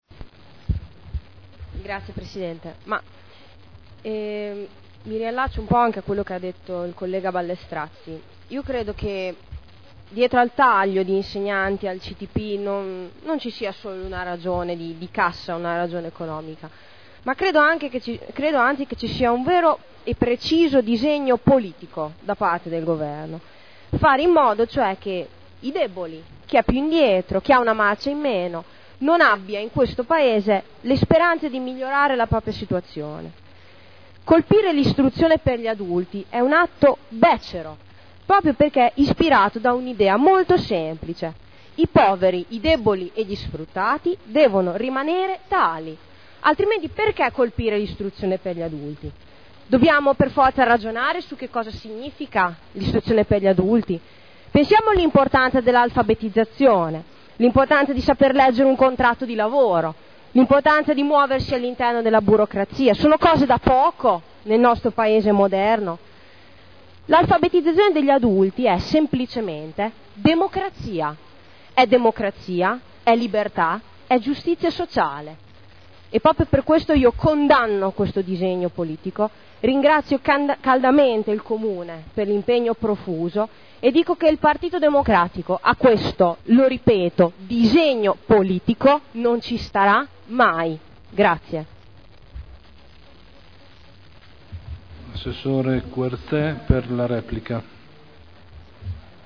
Giulia Morini — Sito Audio Consiglio Comunale